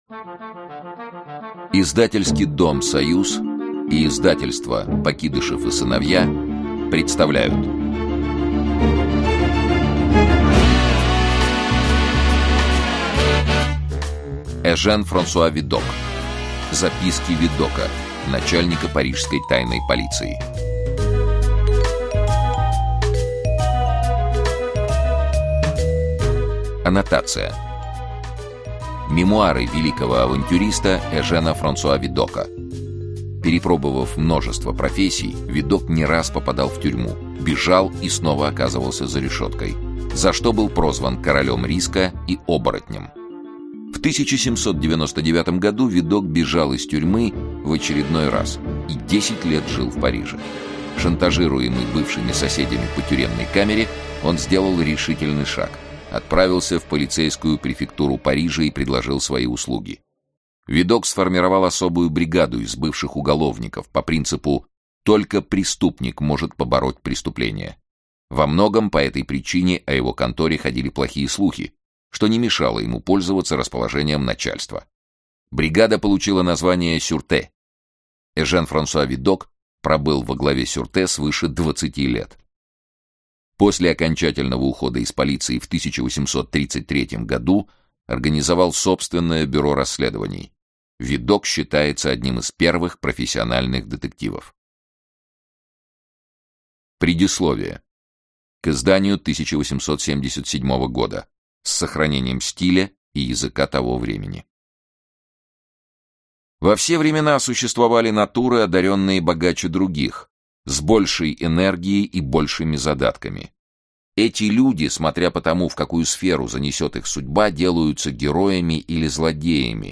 Aудиокнига Записки Видока, начальника Парижской тайной полиции Автор Эжен Видок Читает аудиокнигу Сергей Чонишвили.